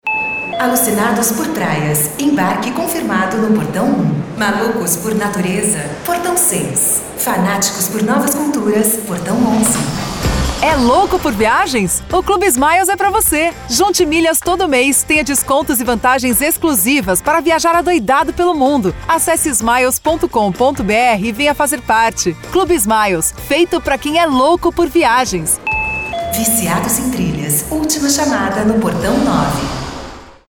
Feminino
Voz Padrão - Grave 00:30